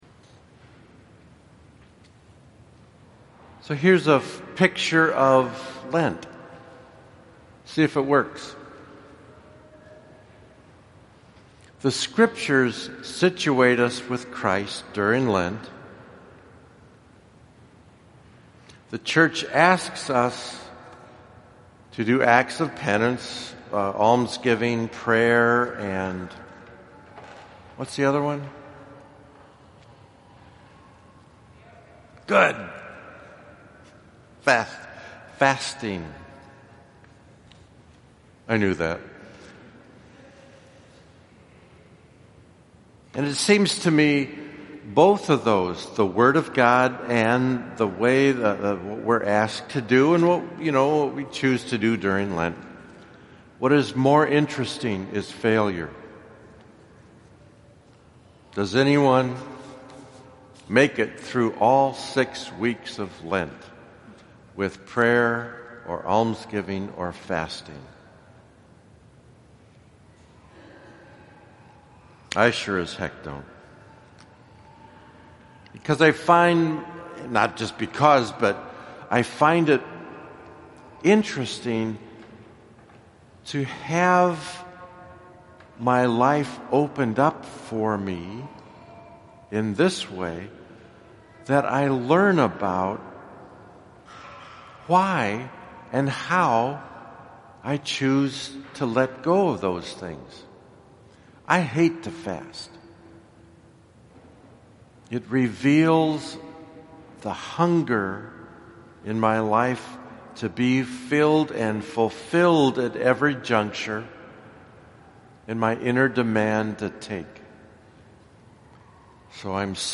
1st SUN LENT – Homily #1 audio